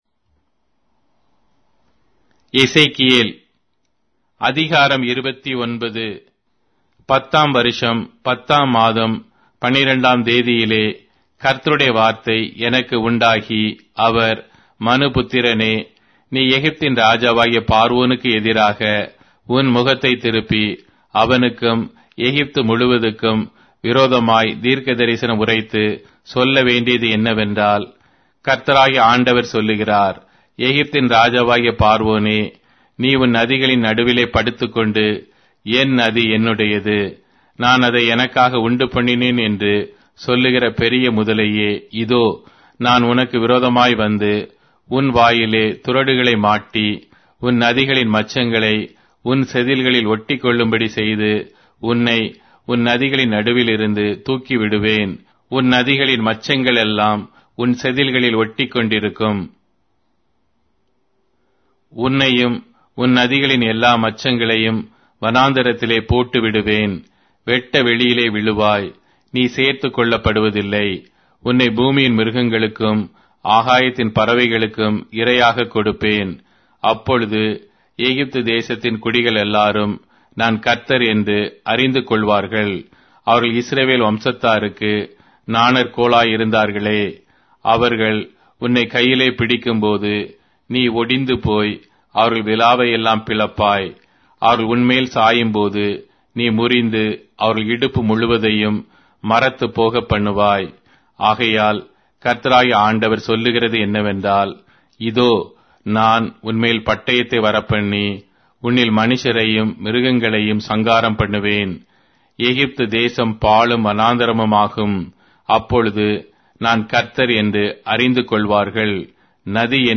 Tamil Audio Bible - Ezekiel 25 in Orv bible version